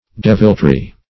Deviltry \Dev"il*try\, n.; pl. Deviltries.